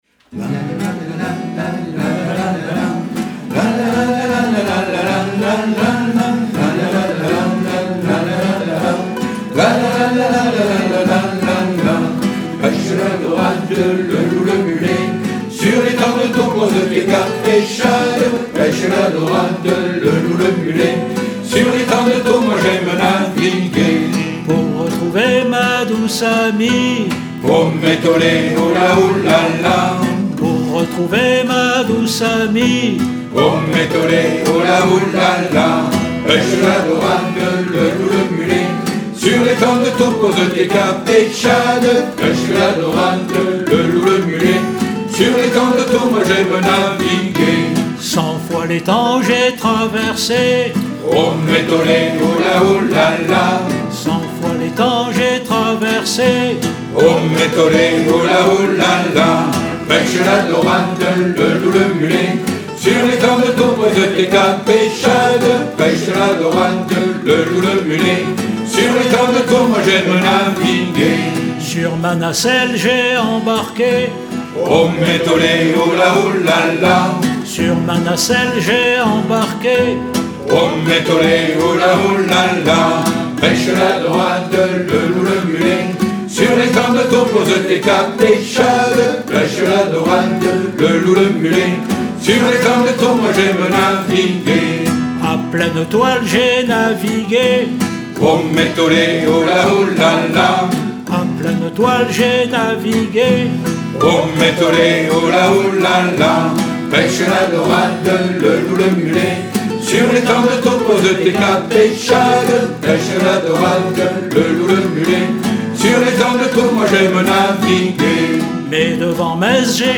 Refrain (plusieurs fois au début)